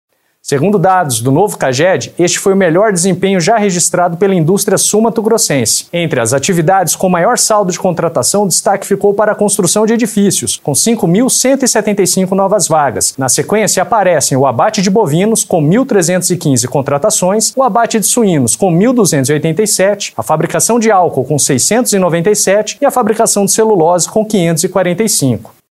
Em entrevista à FM Educativa MS